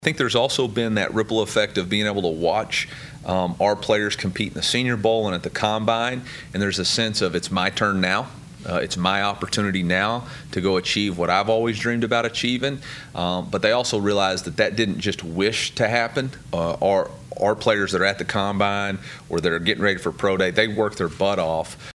Football Press Conference – February 26, 2024
COLUMBIA, Mo. – University of Missouri football head coach Eliah Drinkwitz met with the media on Monday, Feb. 26, 2014 prior to the start of the 15-practice spring football session on Tuesday, Feb. 27.